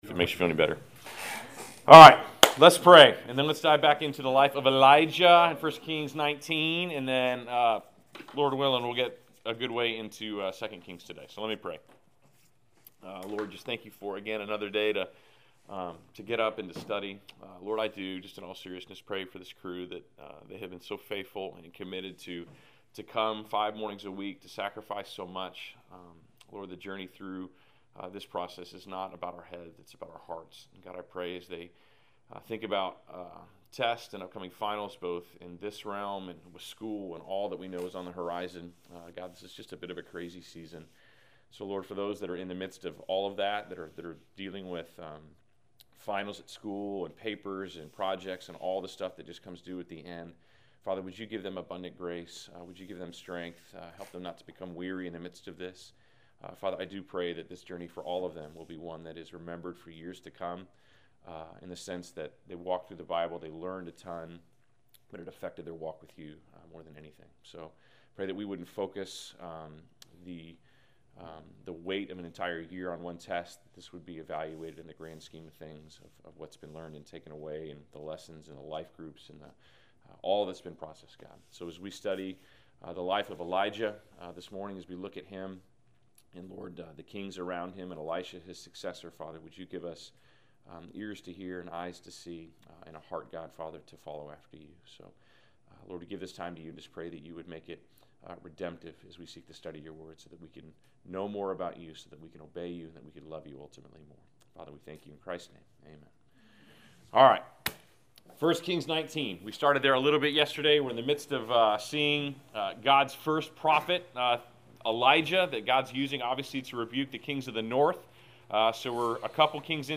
Class Session Audio April 15